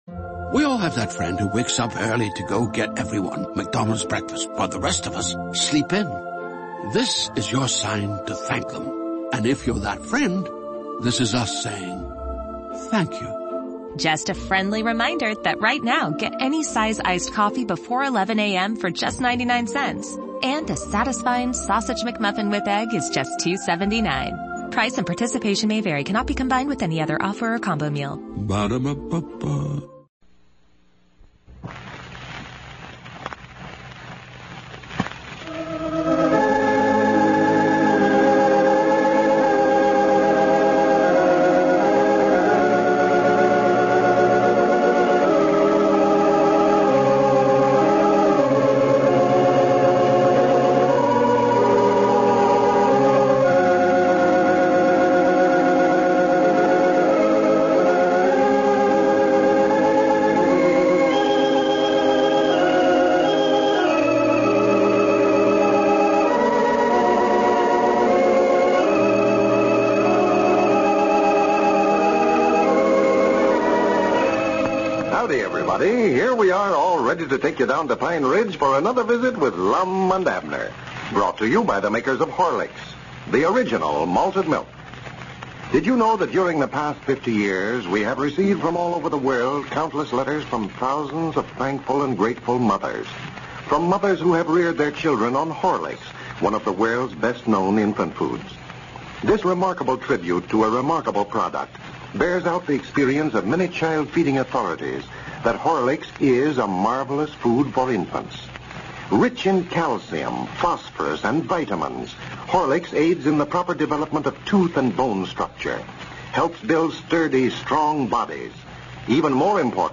A classic radio show that brought laughter to millions of Americans from 1931 to 1954.